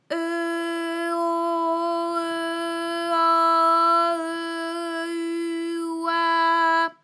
ALYS-DB-001-FRA - First, previously private, UTAU French vocal library of ALYS
e_o_e_au_e_u_oi.wav